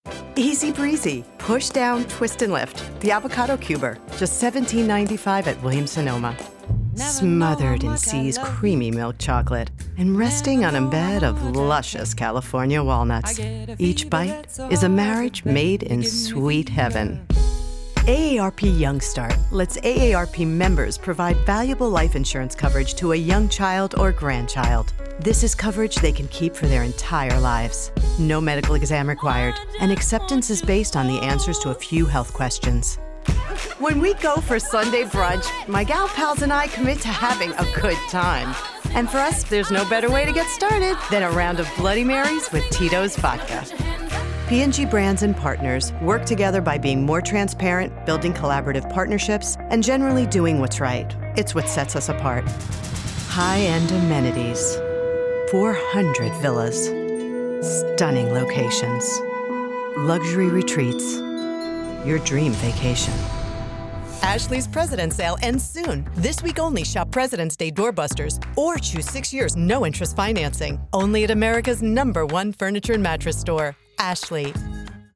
My voice is described as rich, warm, mature and versatile.